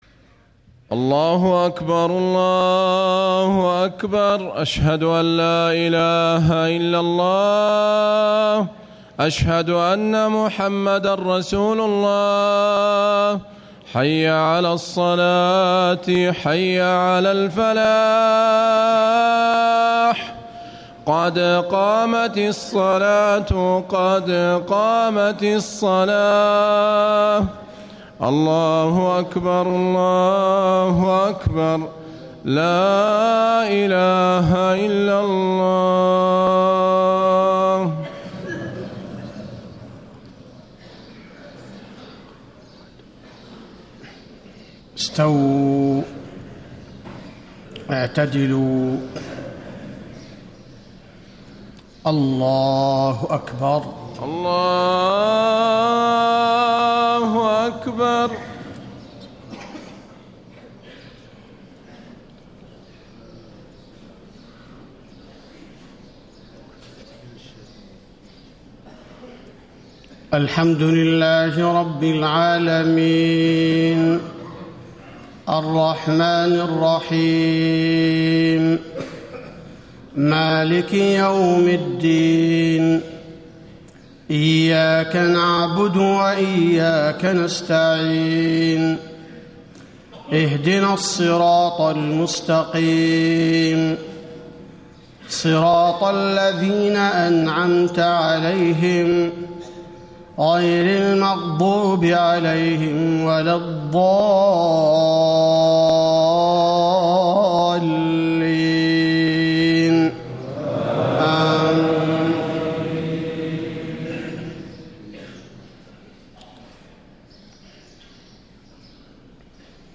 صلاة الفجر 9 - 1 - 1435هـ سورة نوح > 1435 🕌 > الفروض - تلاوات الحرمين